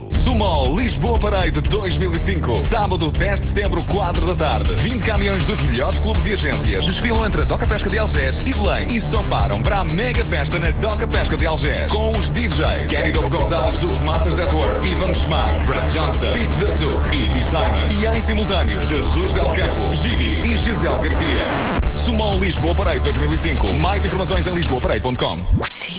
clique para ouvir o spot) e até ao dia 1 de Setembro foi investido na mesma 4502 euros para 21 inserções, a preço tabela.